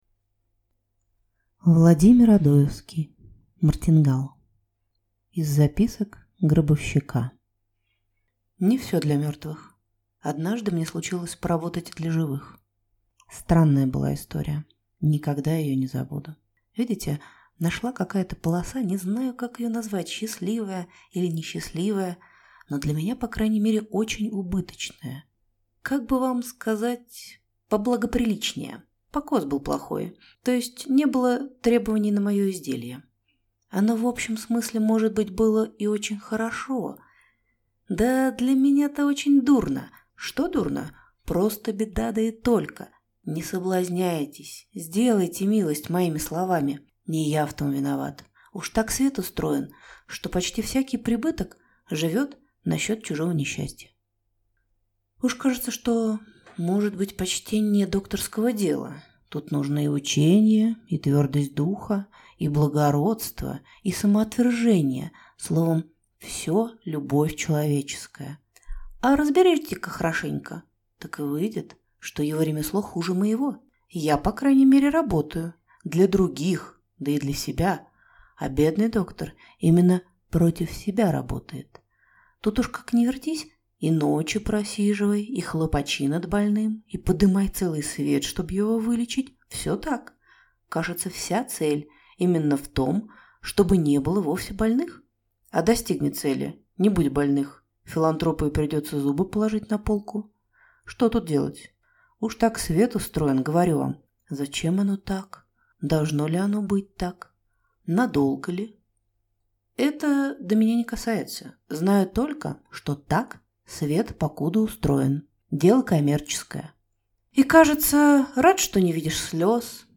Аудиокнига Мартингал | Библиотека аудиокниг